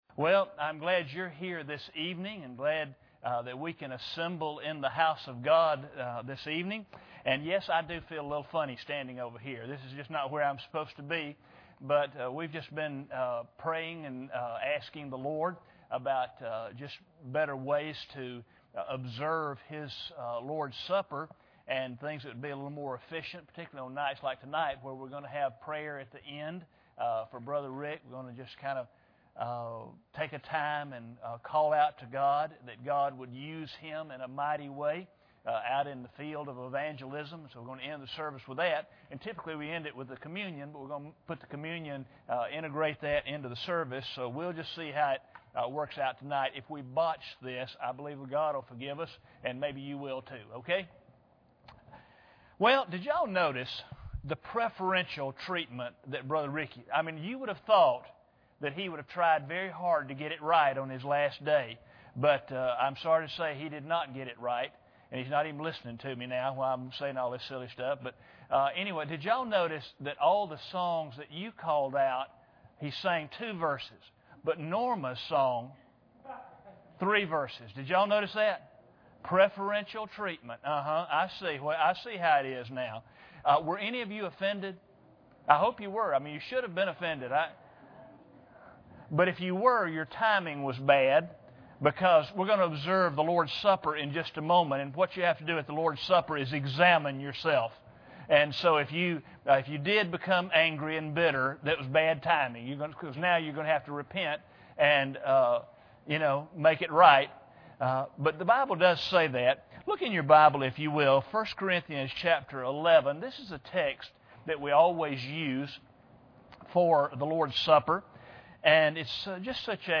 1 Corinthians 11:28 Service Type: Sunday Evening Bible Text